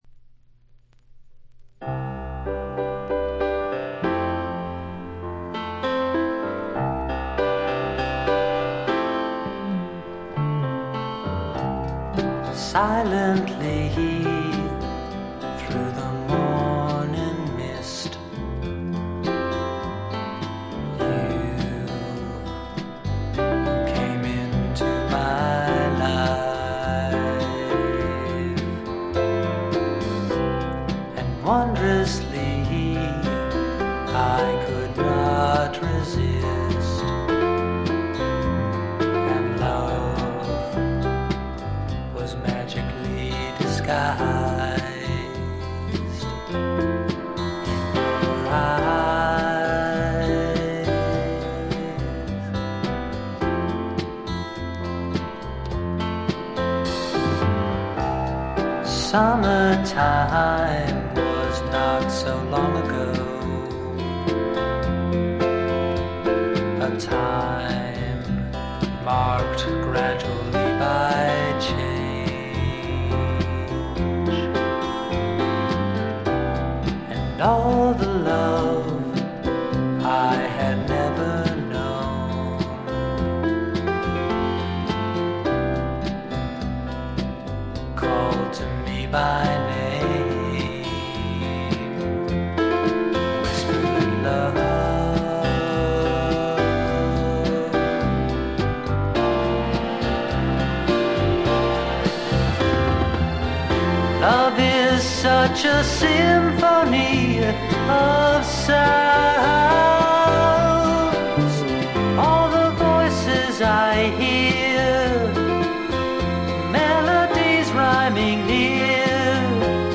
テキサスのシンガー・ソングライターが残した自主制作快作です。。
きらきらときらめくアコギの音、多くのマニアをノックアウトしたメローでクールな楽曲、時おりふっと見せるダウナーな感覚。